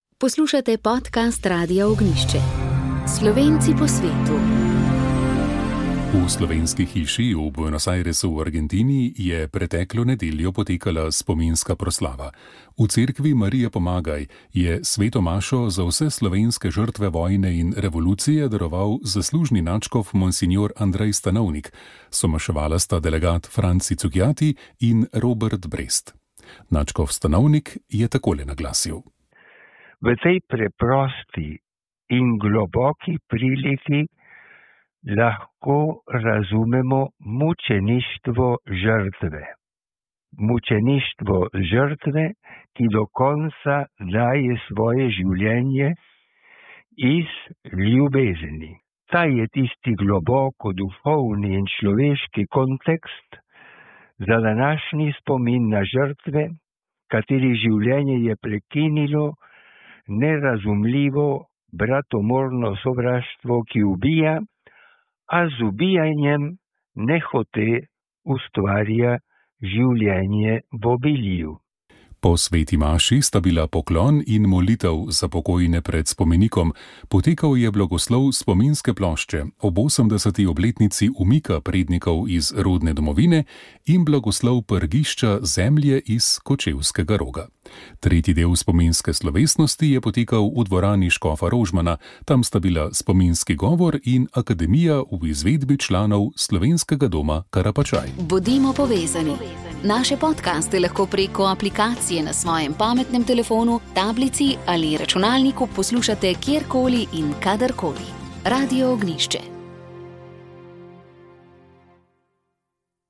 Še naprej odmeva navijaški incident na nedeljski tekmi med nogometaši Mure in Maribora, v katerem je bilo poškodovanih več ljudi, in je povzročil množično ogorčenje in pozive pristojnim k ukrepanju. Za komentar smo poklicali tudi murskosoboškega škofa Petra Štumpfa, ki je spregovoril tudi o postnem času v katerem smo.